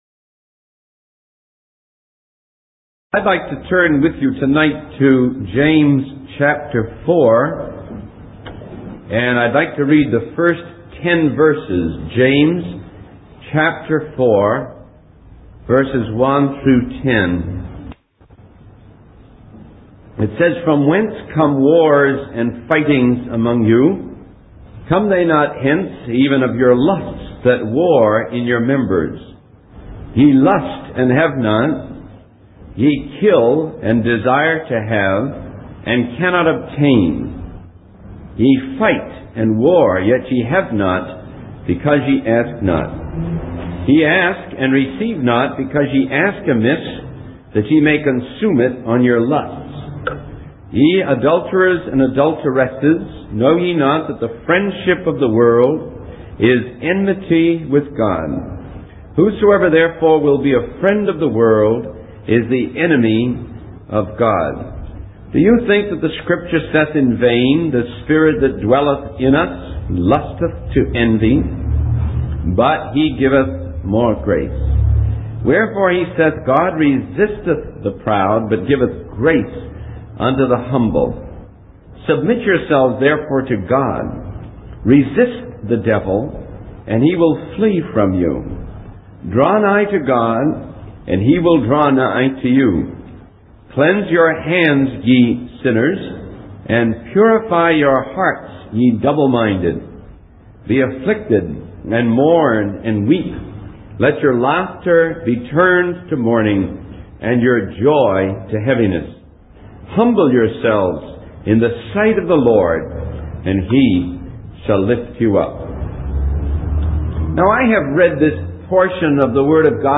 In this sermon, the preacher discusses the source of conflicts and battles among people, as mentioned in James chapter 4, verses 1 and 2. He emphasizes that these conflicts often arise from selfish desires and lusts within individuals.